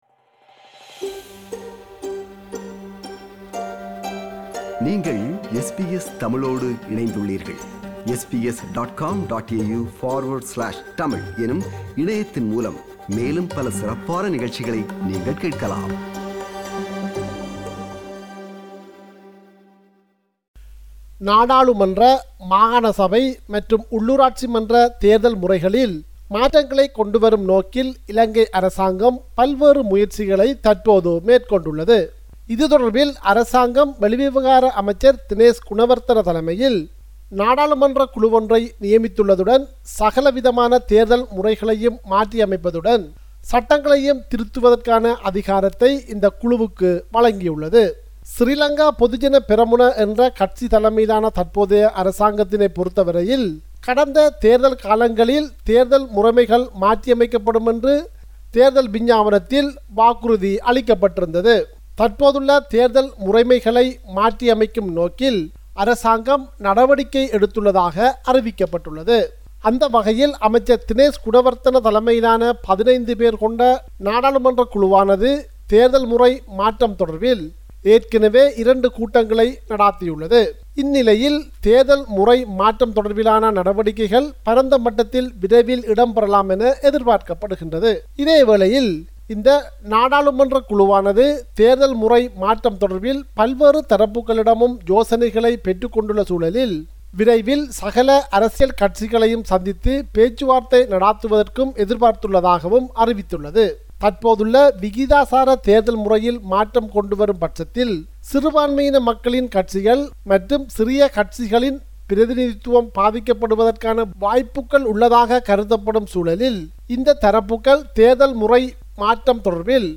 இது குறித்த விவரணம்